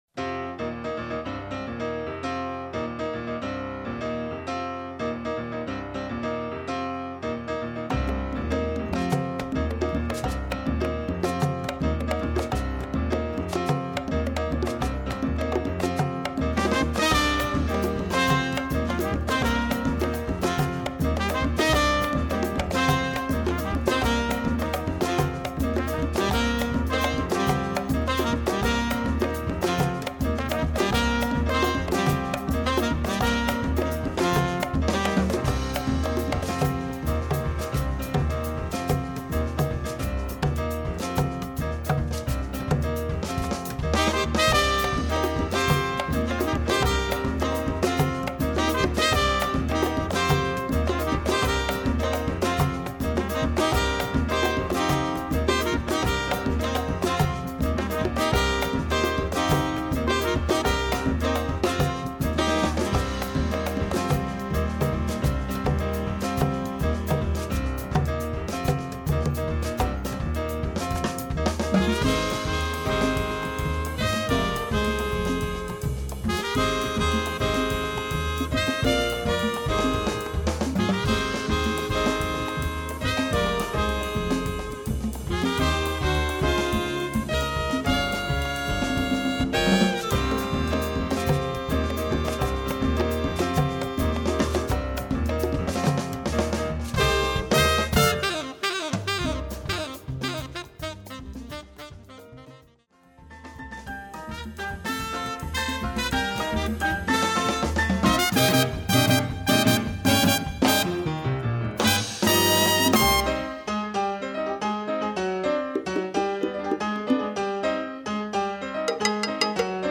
Category: combo
Style: mambo
Solos: open
Instrumentation: trumpet, tenor, rhythm section